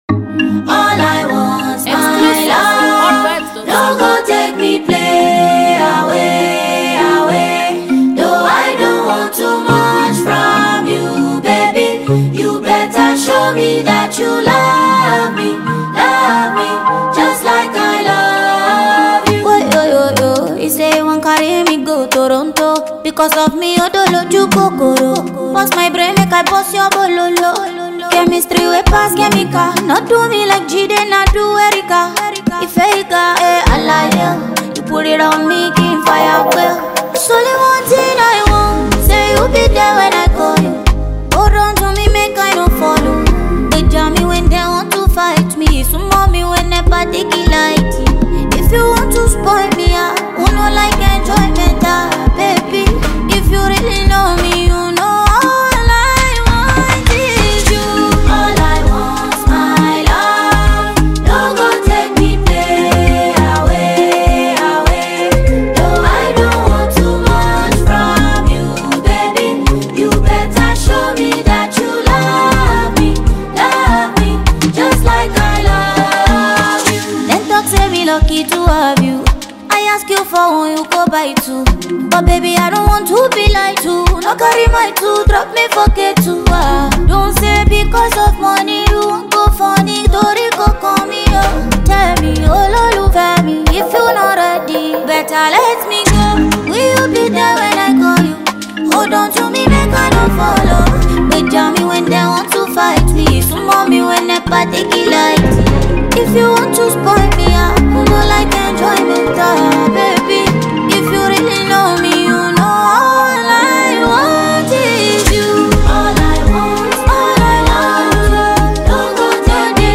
Nigerian award multiple winning female singer